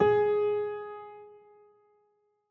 files AfterStory/Doki Doki Literature Club/game/mod_assets/sounds/piano_keys
G4sh.ogg